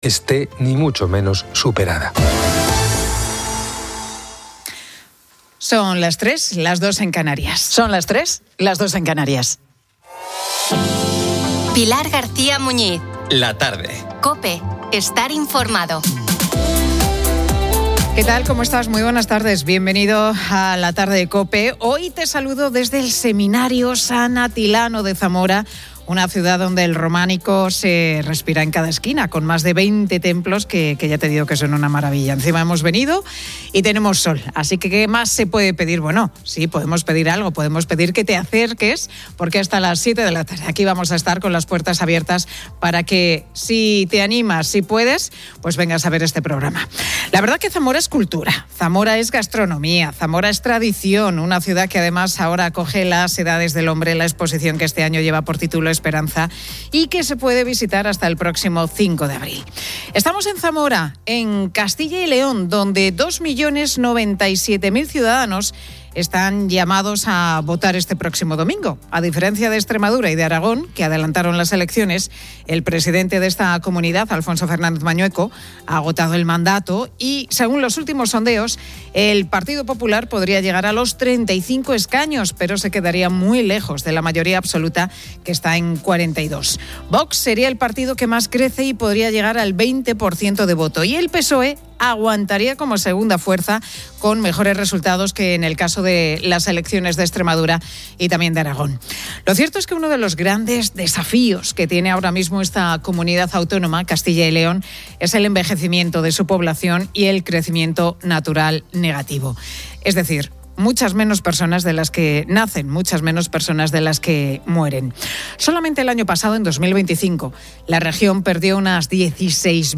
La Tarde 15:00H | 10 MAR 2026 | La Tarde El programa "La Tarde de COPE" se emite desde Zamora, ciudad que acoge la exposición "Las Edades del Hombre" y refleja la despoblación en Castilla y León.